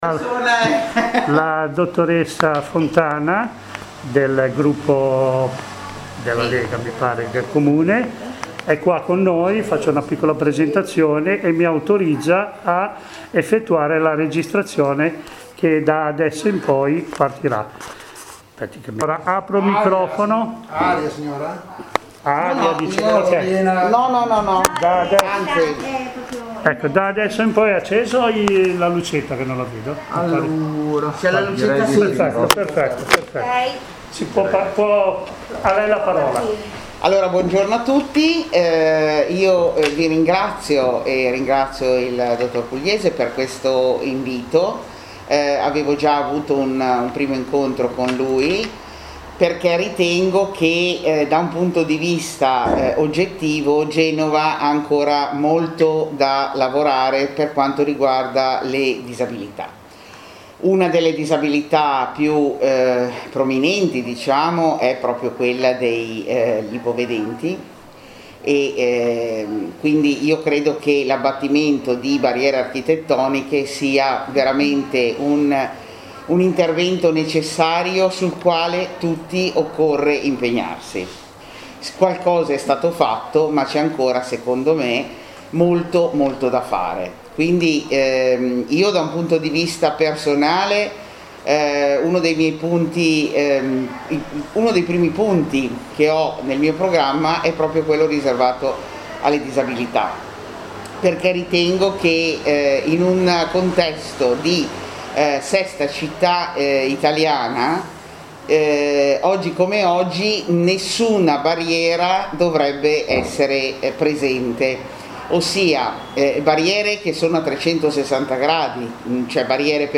Disponibile la registrazione dell'incontro dell'11 settembre con la Capogruppo Lega del Consiglio comunale genovese Lorella Fontana!